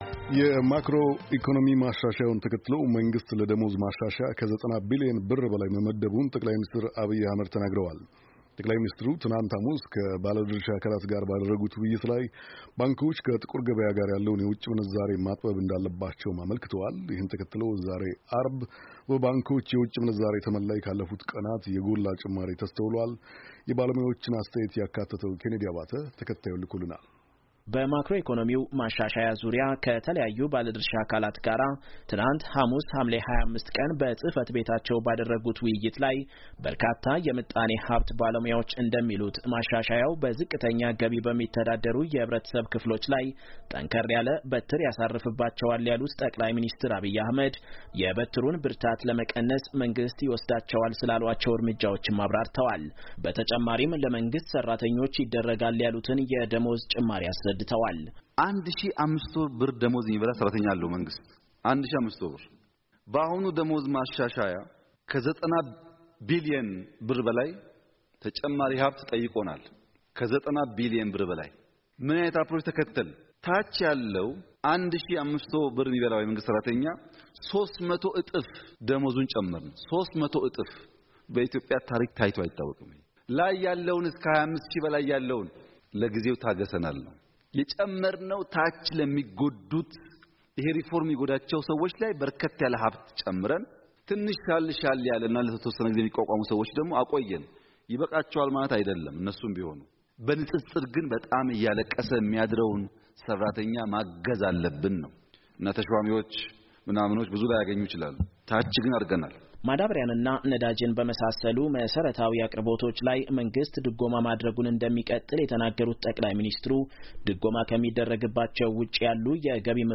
የባለሞያዎችን አስተያየት የተካተተበትን ዘገባ ከተያያዘው ፋይል ይከታተሉ።